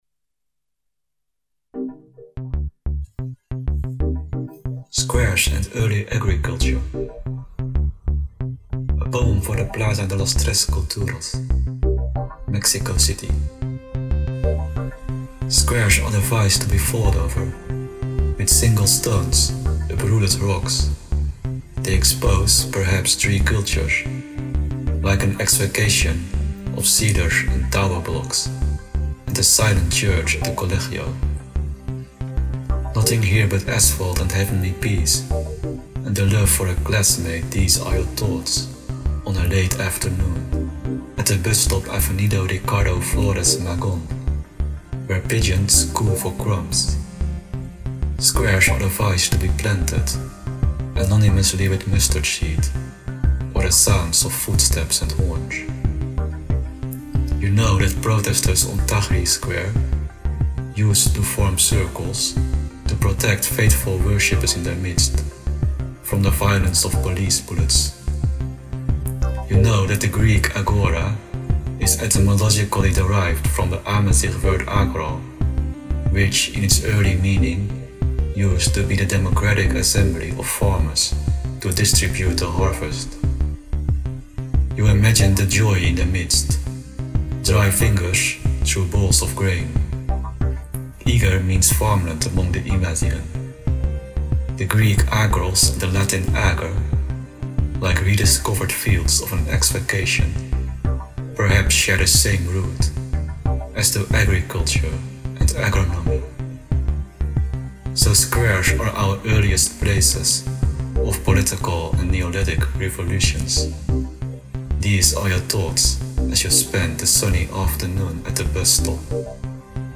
A collection of textured beats featuring artists’ contributions to Ons Klyntji 2023.